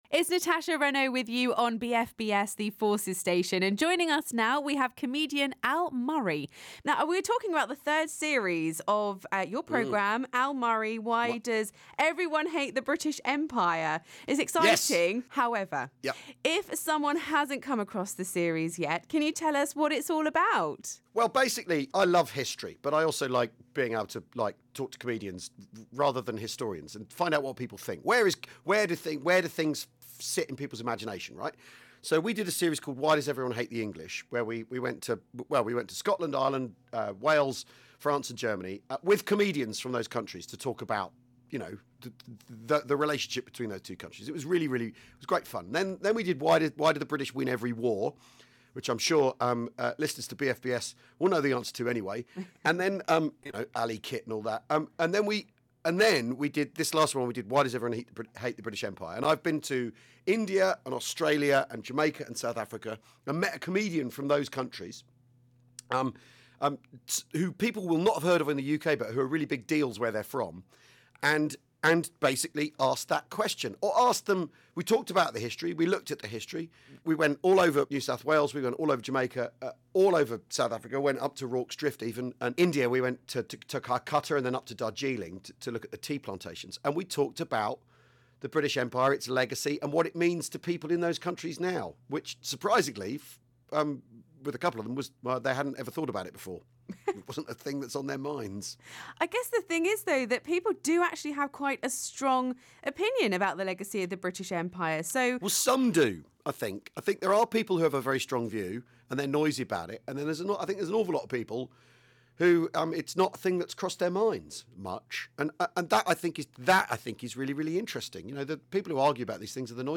Al Murray talks about his new TV show